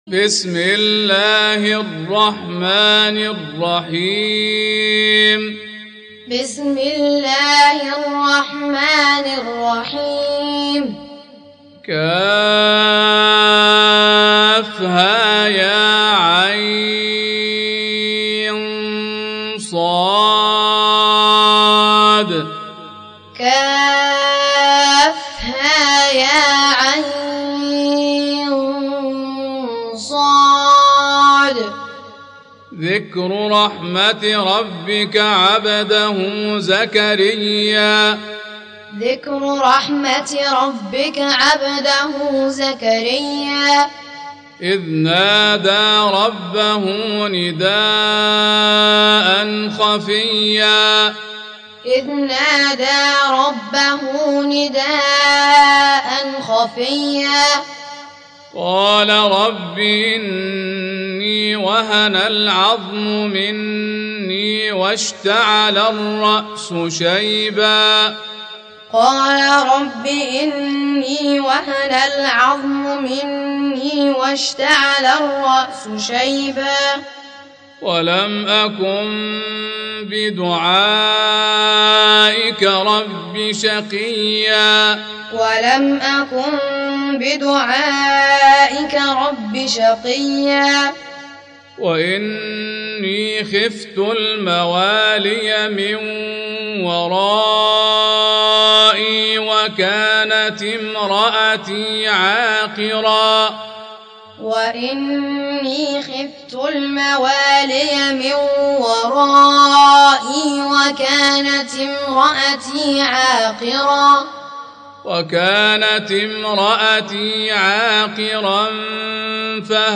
Surah Repeating تكرار السورة Download Surah حمّل السورة Reciting Muallamah Tutorial Audio for 19. Surah Maryam سورة مريم N.B *Surah Includes Al-Basmalah Reciters Sequents تتابع التلاوات Reciters Repeats تكرار التلاوات